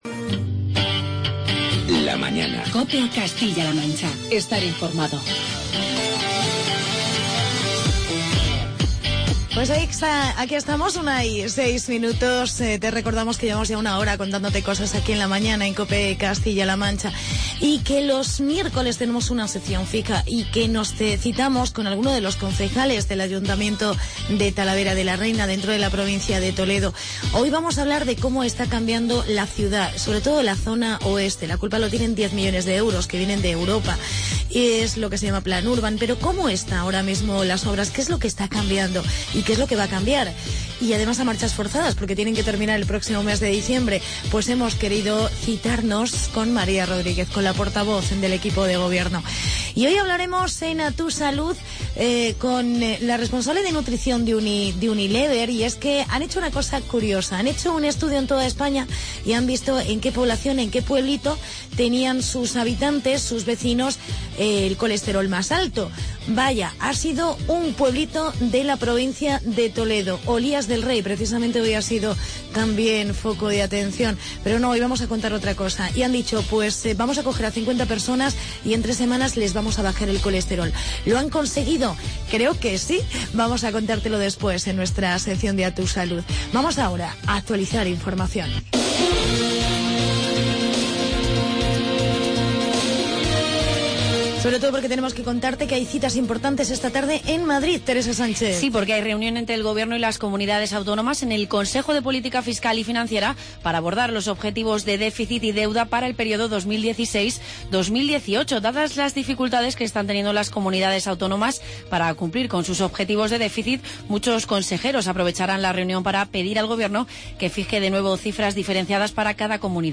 Entrevista con la concejal talaverana